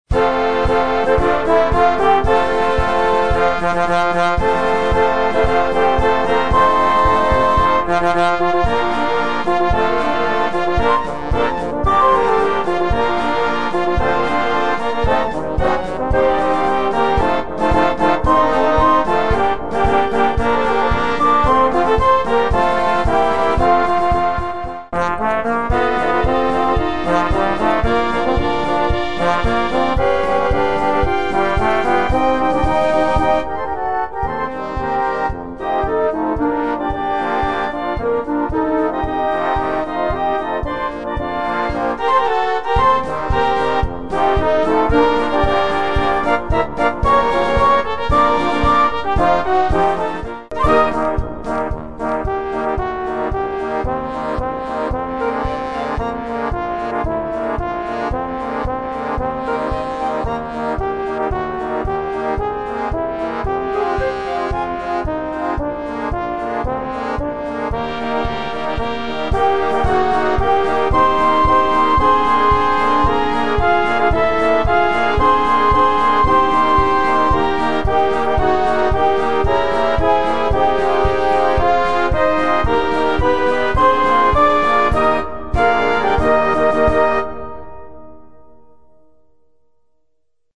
Blasmusik:
Märsche: